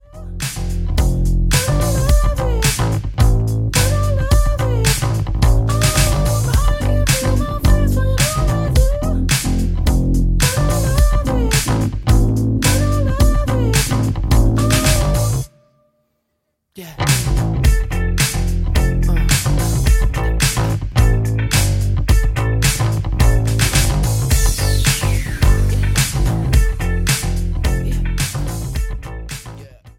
Am
Backing track Karaoke
Pop, 2010s